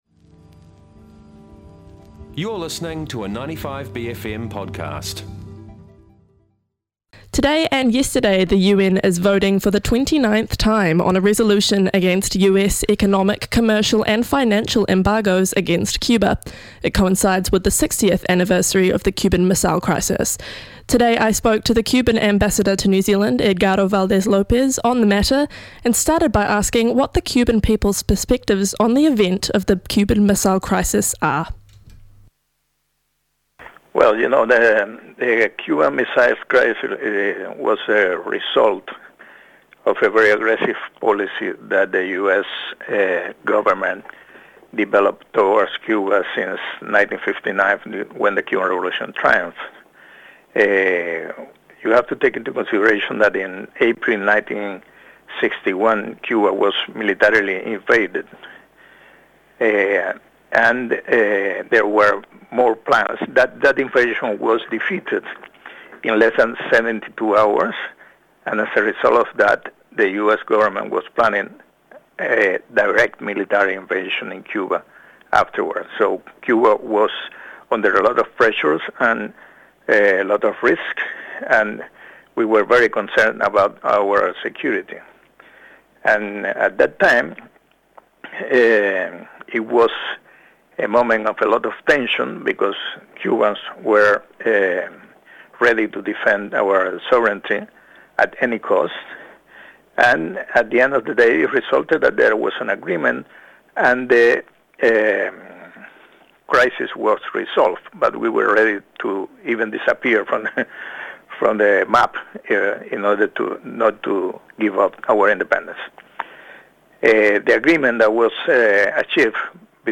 speaks to Cuban Ambassador to New Zealand, Mr. Edgardo Valdés López, about the Cuban perspective and the sanctions that the US still hold against Cuba to mark the 60th anniversary of the event.